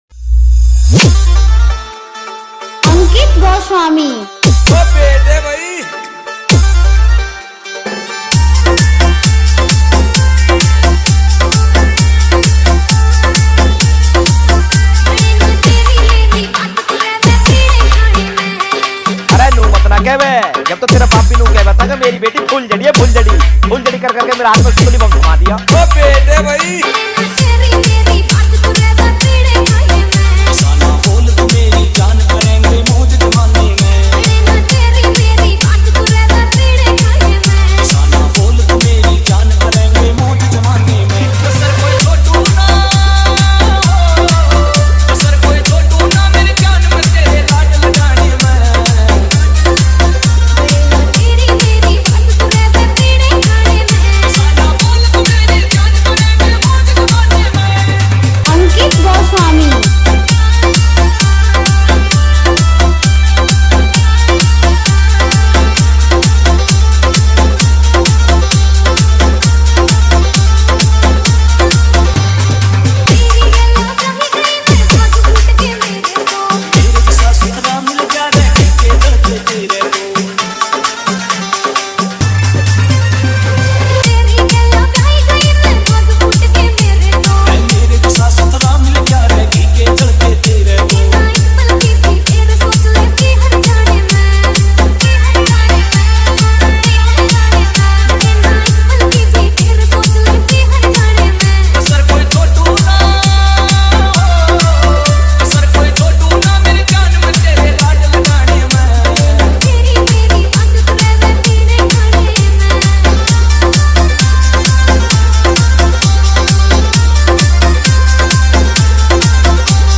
Haryanvi Remix Song